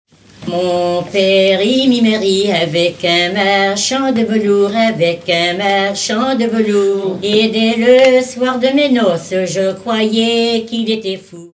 Origine : Bretagne (Pays de Redon) Année de l'arrangement : 2015